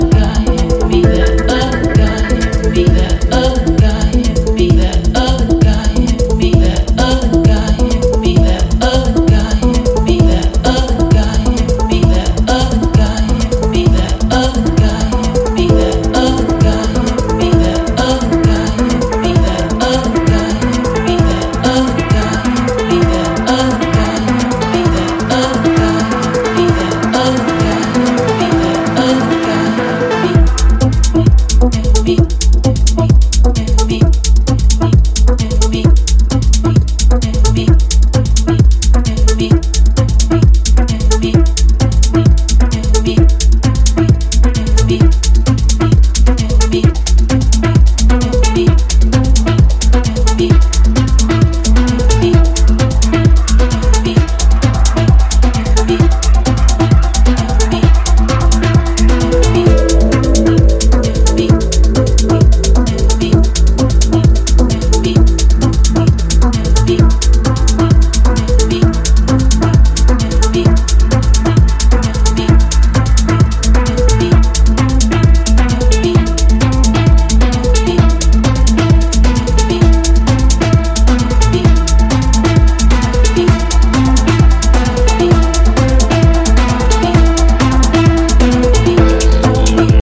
本作ではよりアブストラクトなテクノの領域に分け入っています。